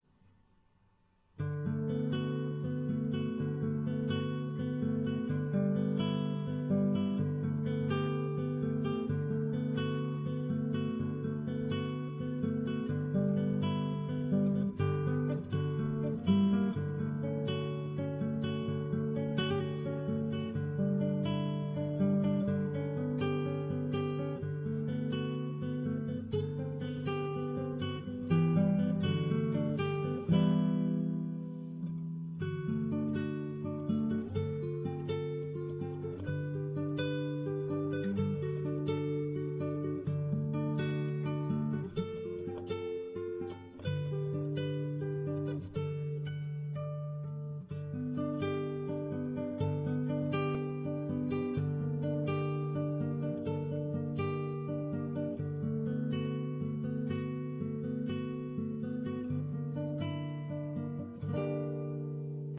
Tuning: EADGBE Key: G maj/min Sample:
RealAudio Format (Myself playing)
arrangement for guitar
It's really easy and sounds very cool!!!